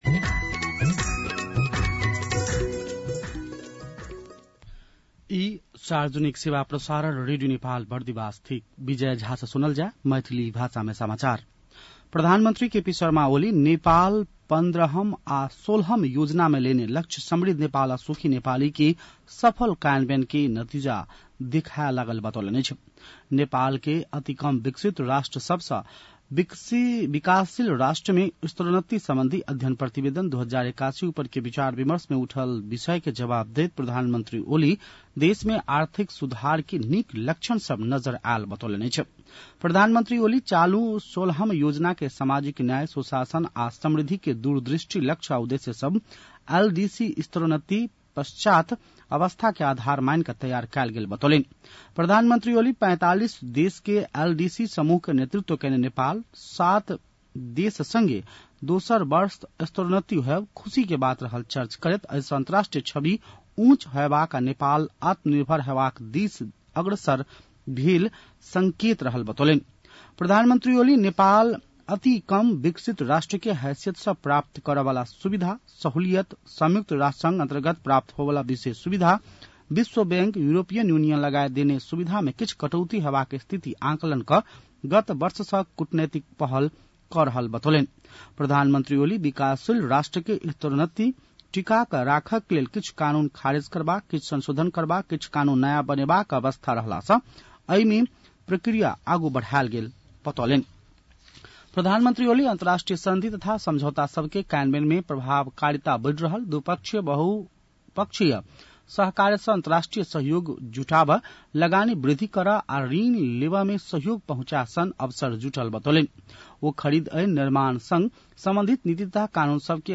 मैथिली भाषामा समाचार : १४ साउन , २०८२
Maithali-news-4-14.mp3